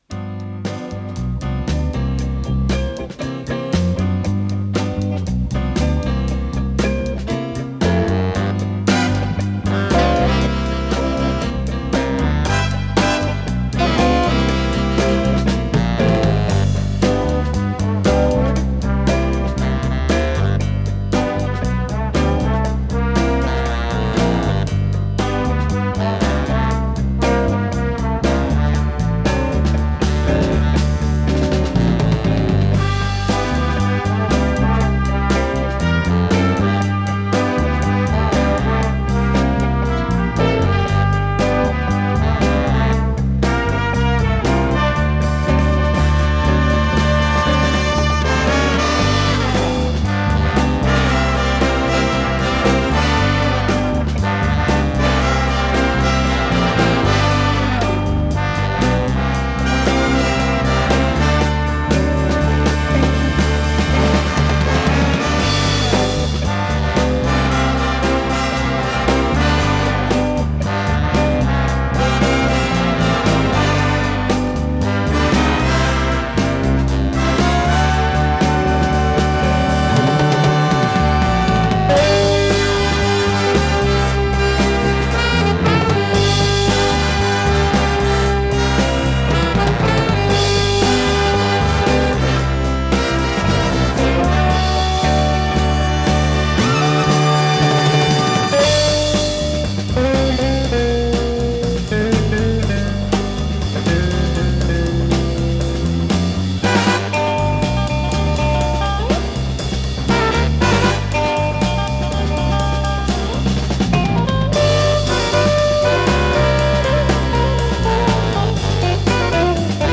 ПодзаголовокСоль мажор
Скорость ленты38 см/с
ВариантДубль моно